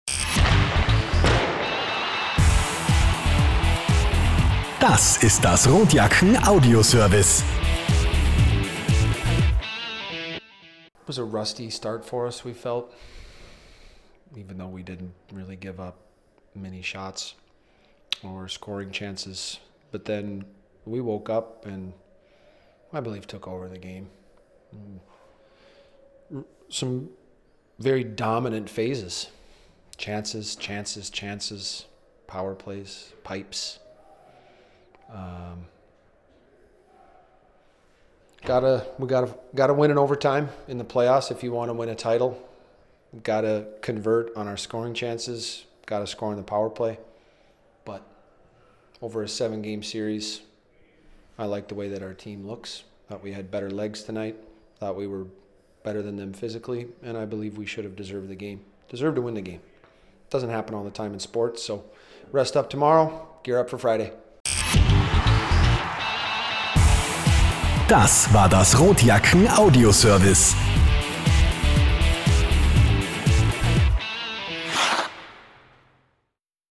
Post Game-Kommentar
Heidi Horten-Arena, Klagenfurt, AUT, 4.407 Zuschauer